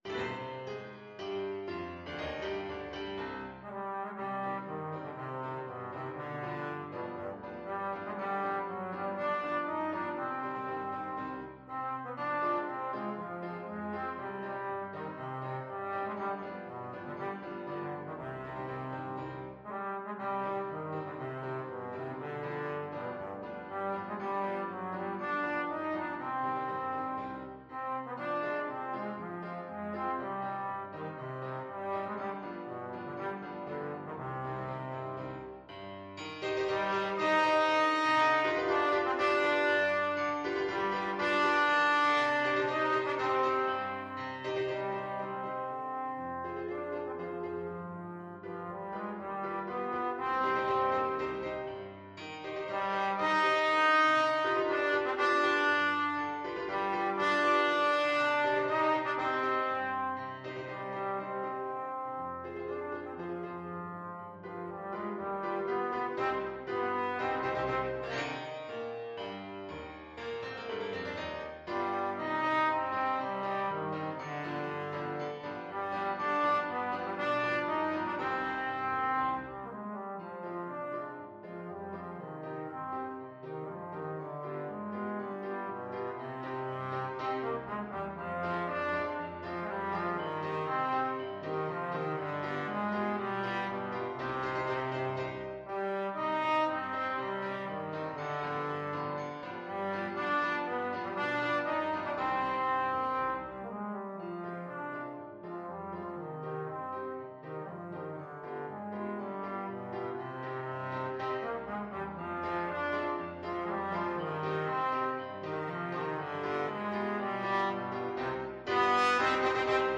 Allegro = c.120 (View more music marked Allegro)
2/2 (View more 2/2 Music)
G3-Eb5
Classical (View more Classical Trombone Music)